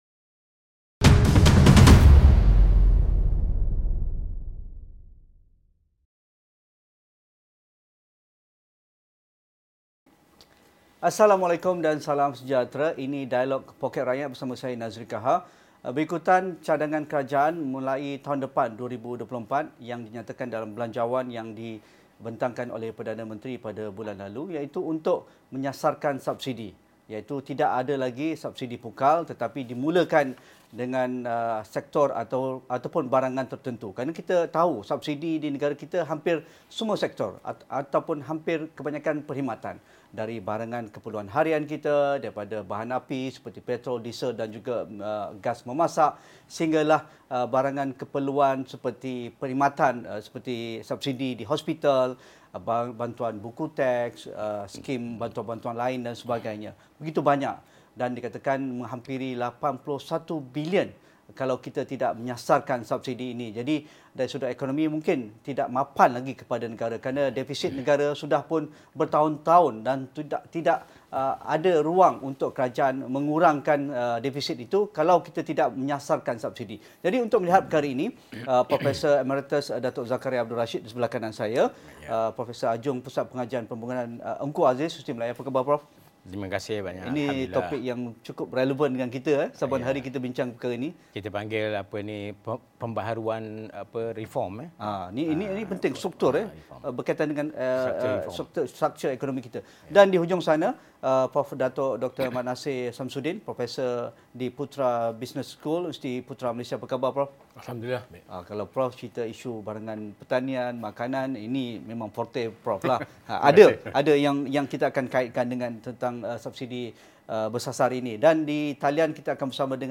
Diskusi dan analisis Dialog Poket Rakyat jam 8:30 malam.